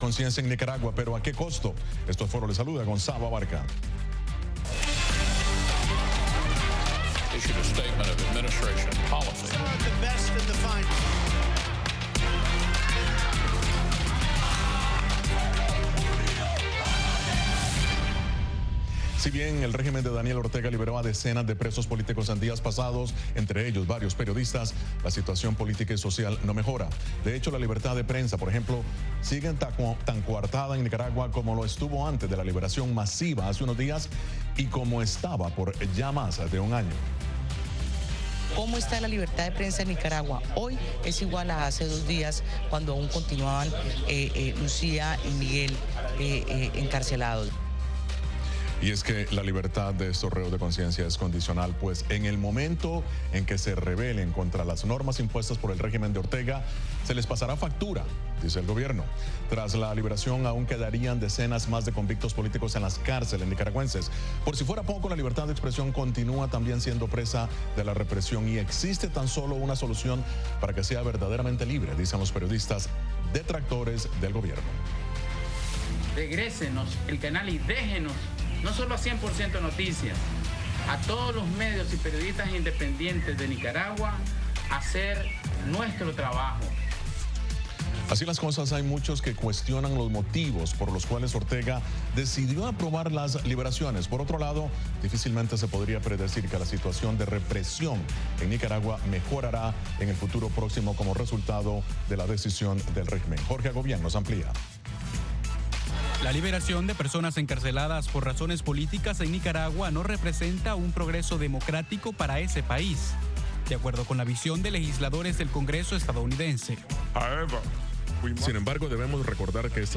Programa de análisis de treinta minutos de duración con expertos en diversos temas.